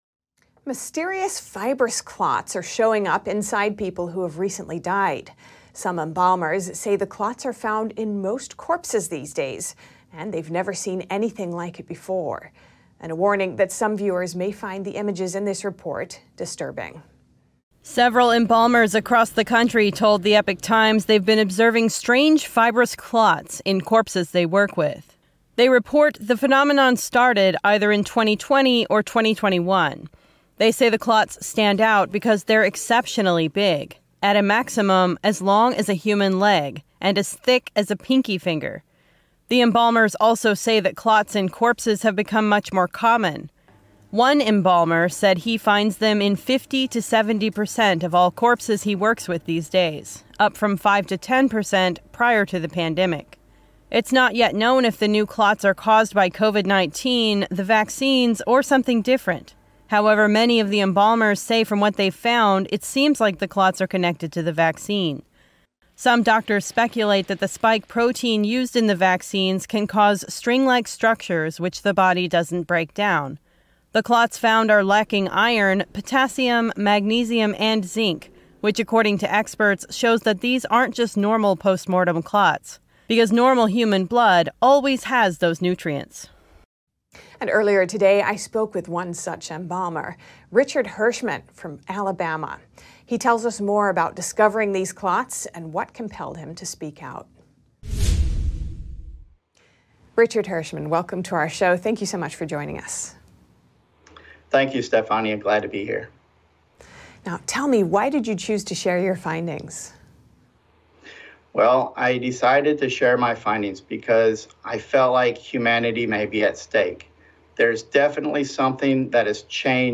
ריאיון מיוחד: קרישי דם מסתוריים בגופות נפטרים